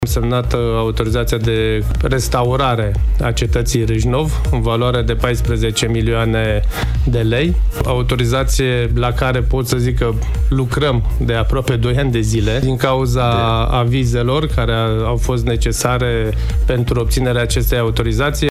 Primarul oraşului Râşnov, Liviu Butnariu: